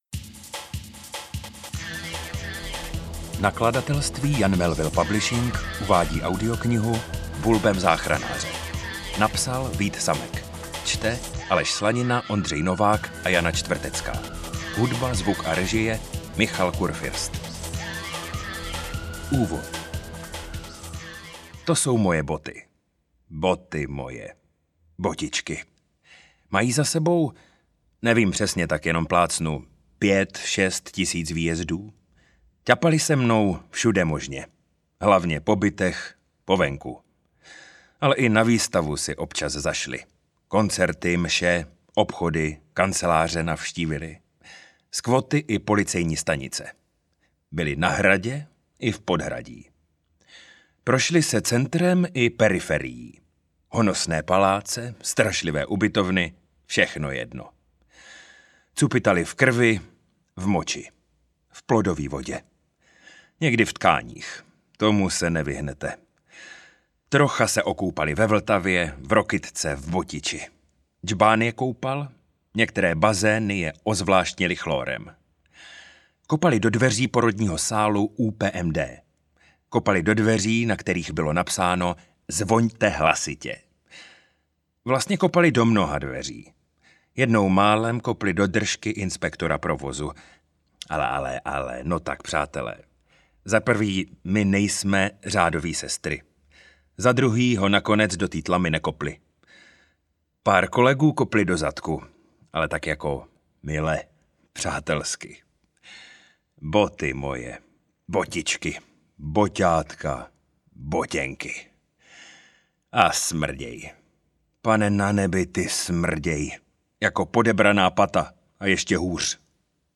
Audiokniha Bulbem záchranáře - Vít Samek | ProgresGuru